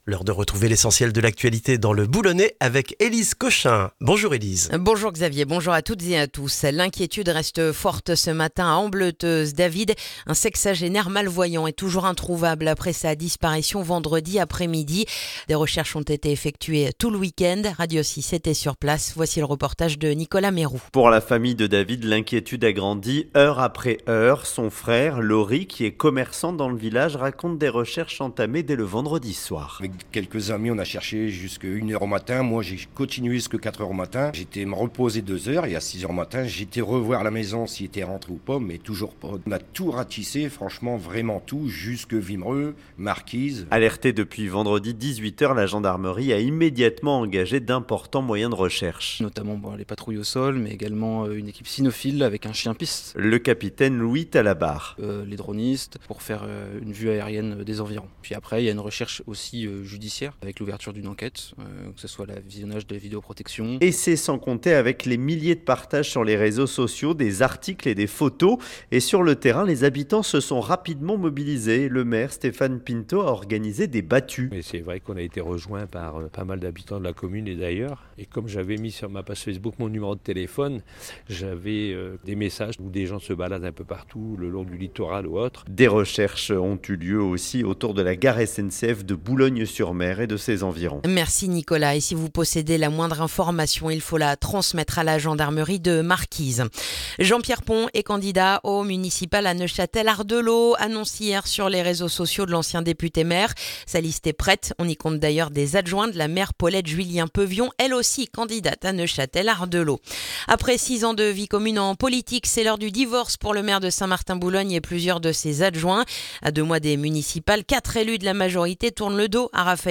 Le journal du lundi 19 janvier dans le boulonnais